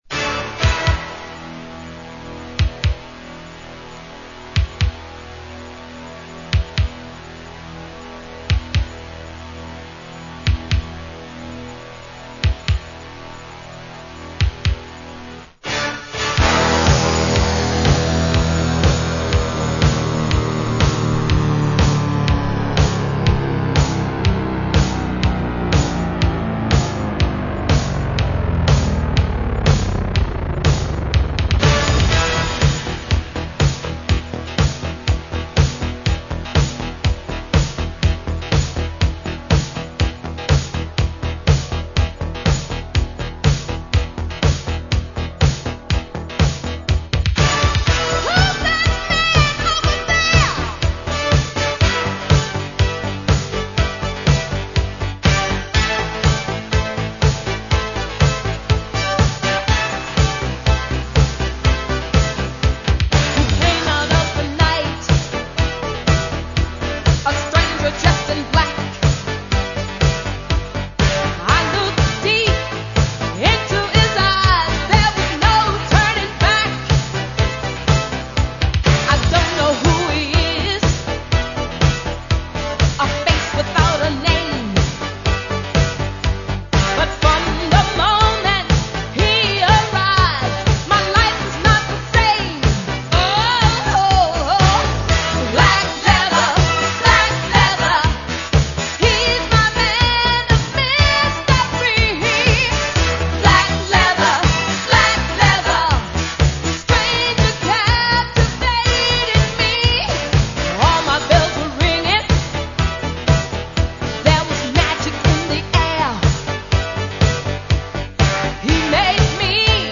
The keyboards and effects add to her excitement.